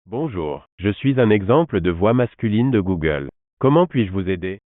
Example of the voices available in our catalog
voix-google2.wav